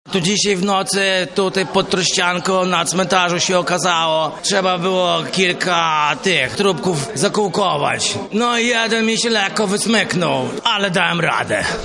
Tam też odbył się zlot fanów, który trwał od piątku do niedzieli.
– tłumaczy bohater